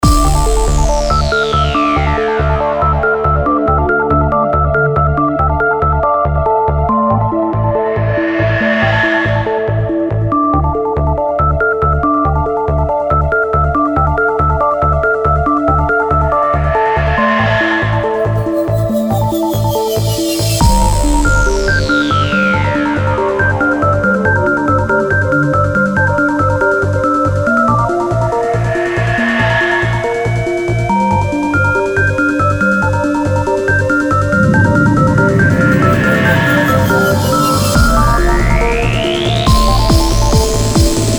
красивые
без слов
космические
космическая музыка
космические эффекты